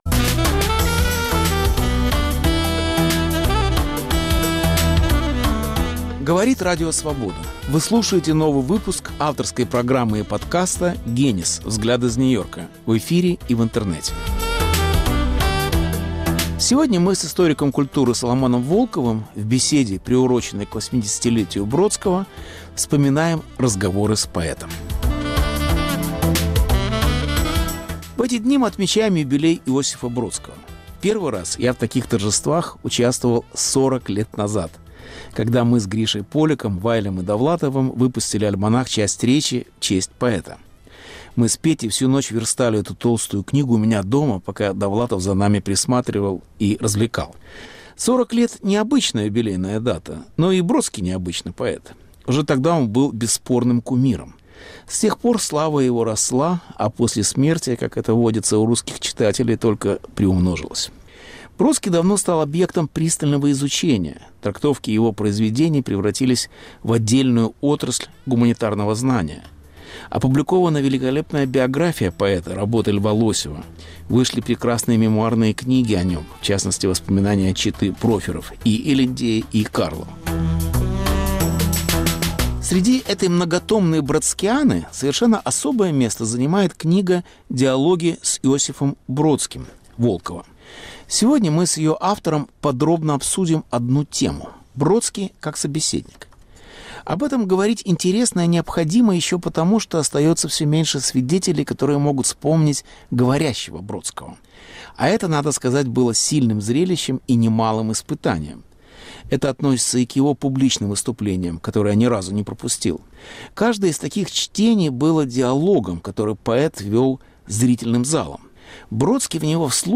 Повтор программы от 18 мая. 2020 Бродский как собеседник. Беседа с Соломоном Волковым, приуроченная к 80-летию поэта